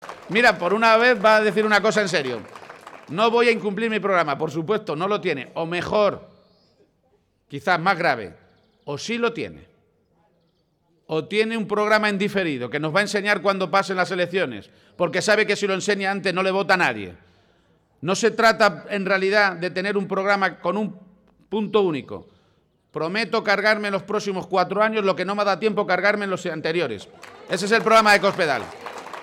De esta forma rechazaba García-Page la falta de interés por los problemas de la gente que demuestran Rajoy y Cospedal y lo hacía en un acto público celebrado a primera hora de la mañana de hoy en la localidad de Yuncos (Toledo)